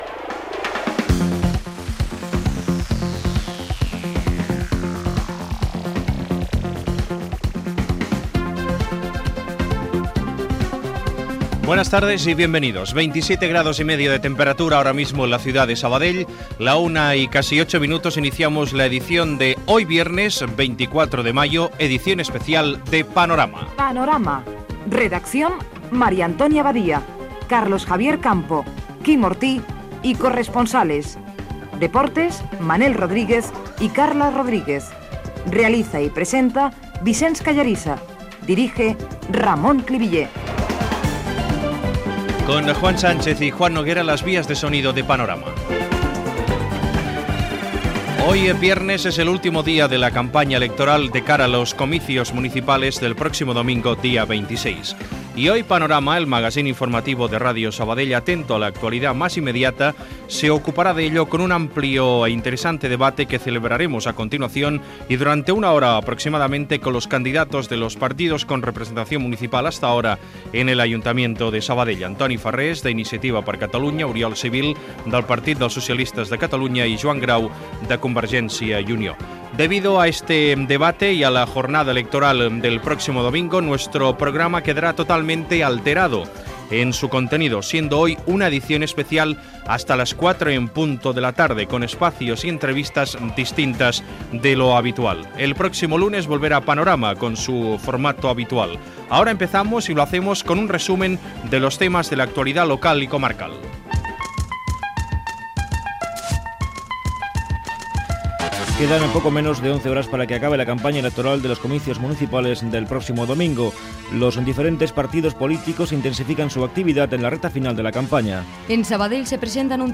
Temperatura, hora, careta i presentació del programa dedicat al debat de les eleccions municipals, resum informatiu
Informatiu